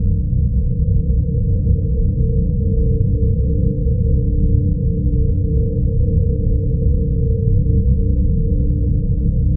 corridor.ogg